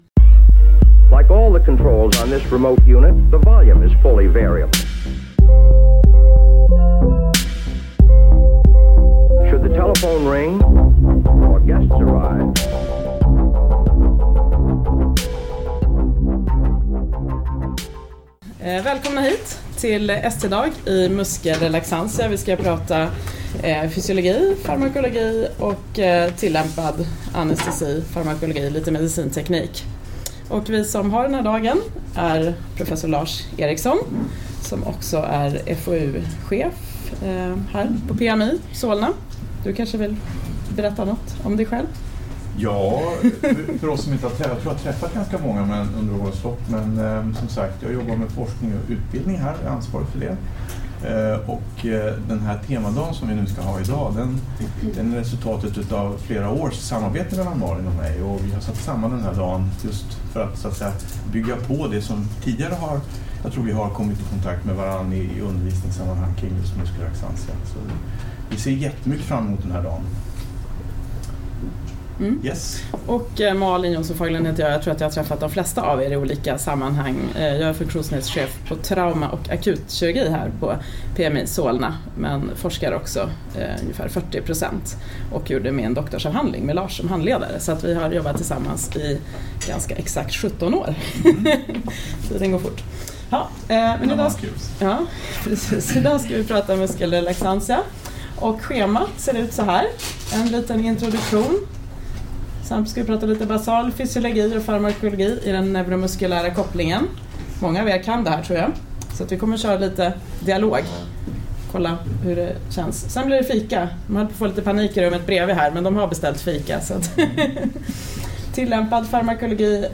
Föreläsningen inledde ST-fredagen om neuromuskulär funktion och muskelrelaxantia 2017.